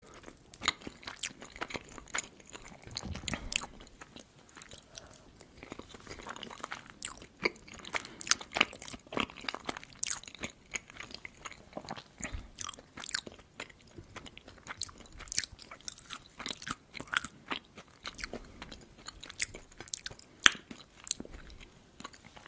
Звуки вареников
Звук, как девушка ест вареники с картошкой (аппетитные звуки жевания)